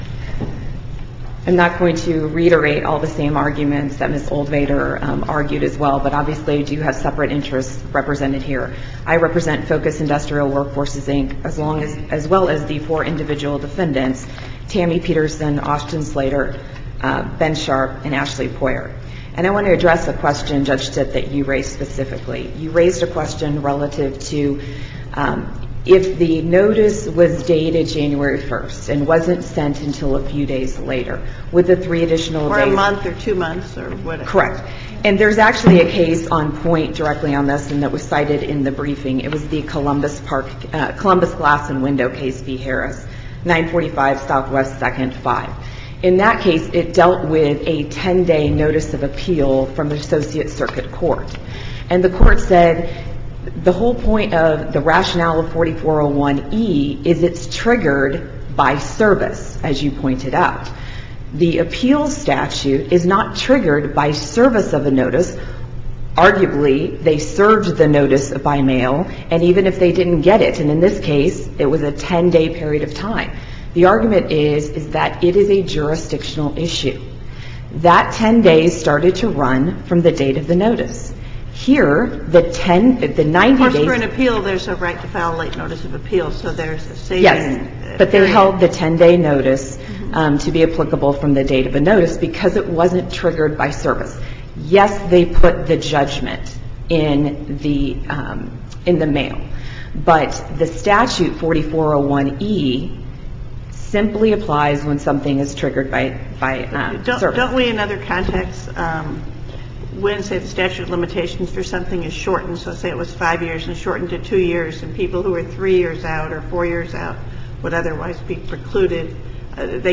MP3 audio file of arguments in SC95976
DOCKET SUMMARIES SUPREME COURT OF MISSOURI 9:30 a.m. Wednesday, May 10, 2017 ____________________________________________________________________________________________________ Note: The first two cases arise out of the same underlying facts and procedure.
Challenge to timeliness, amendment of claims brought under state’s human rights act Listen to the oral argument